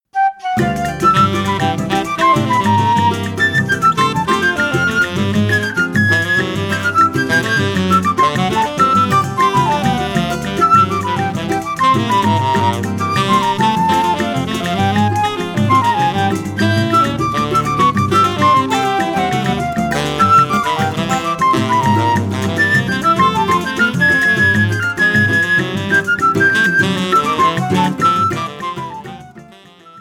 – áudio completo com regional, solo e contraponto.
flauta
saxofone tenor